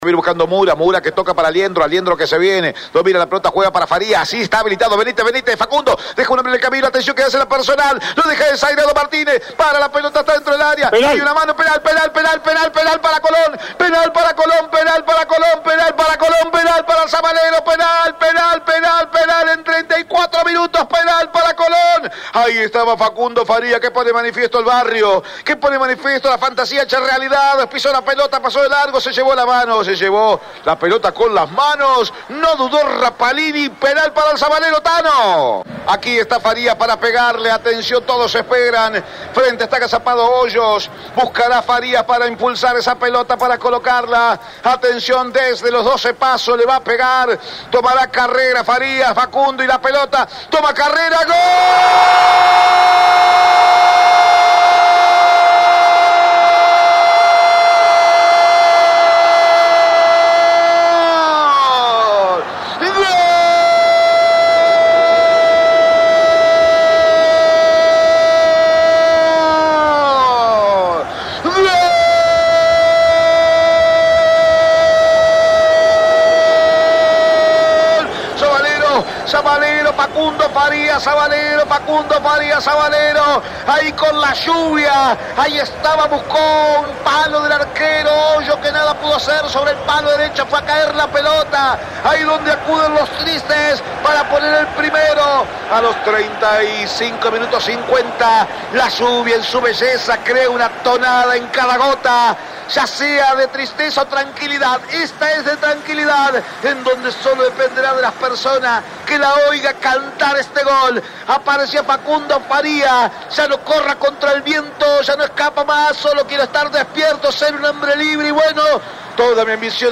Por la fecha N°5 de la Copa de la Liga y En su visita al Estadio José Amalfitani, Colón venció por la mínima a Vélez y se ubica en el primer puesto de la tabla de posiciones.
01-GOL-COLON.mp3